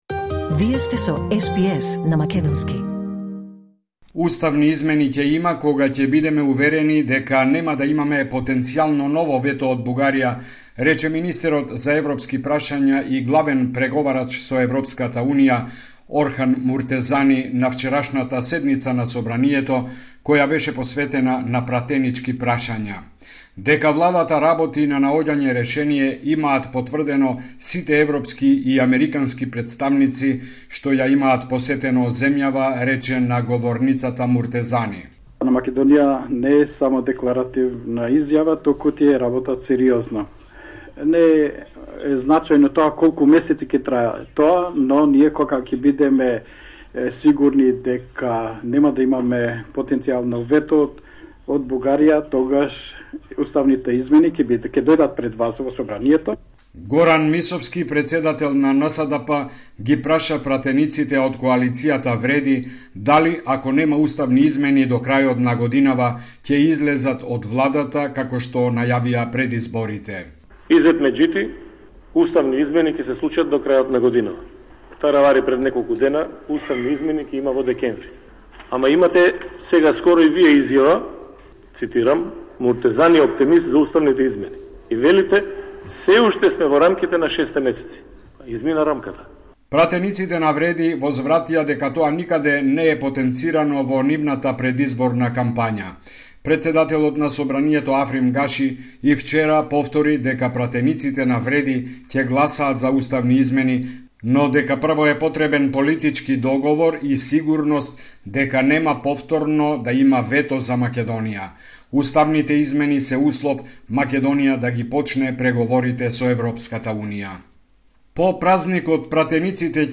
Извештај од Македонија 27 декември 2024